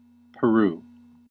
pə-ROO; Spanish: Perú [peˈɾu]; Quechua: Piruw [pɪɾʊw];[9] Aymara: Piruw [pɪɾʊw]
En-us-Peru.ogg.mp3